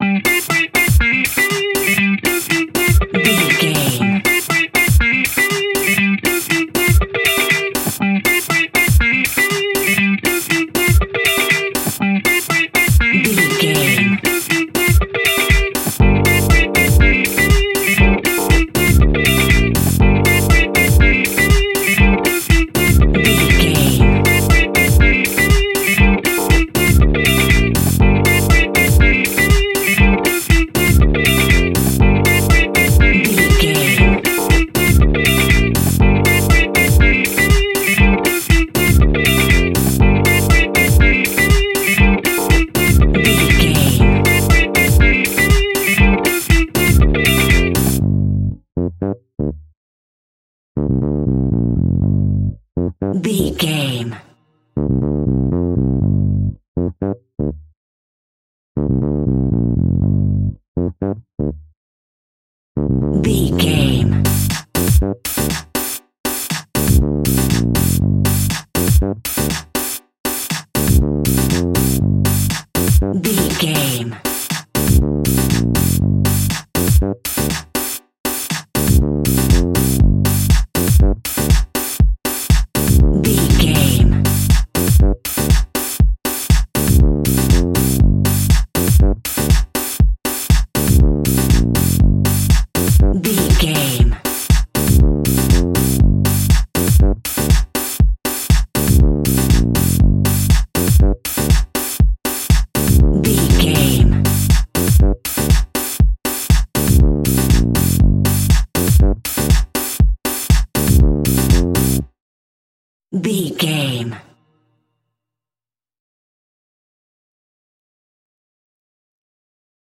Aeolian/Minor
energetic
groovy
drums
bass guitar
electric guitar
electric piano
disco house
upbeat
synth leads
Synth Pads
synth bass
drum machines